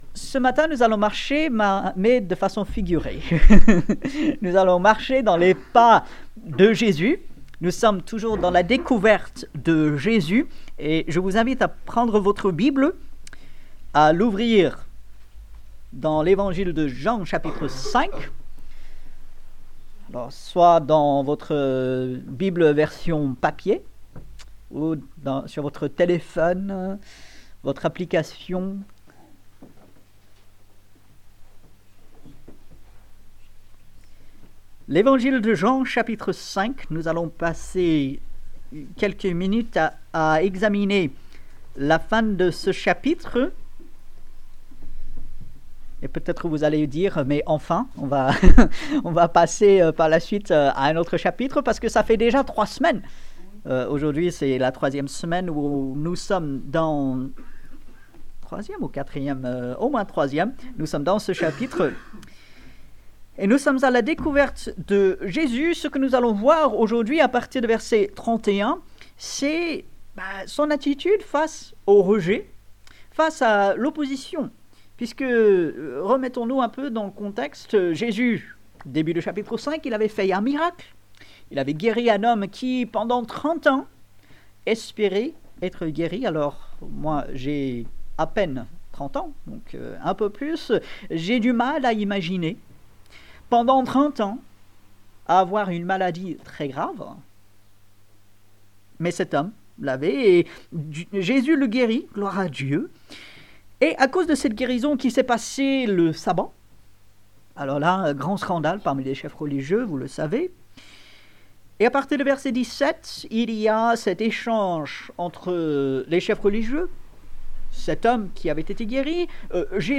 Ici nous vous proposons l'écoute des prédications qui sont apportées le dimanche matin.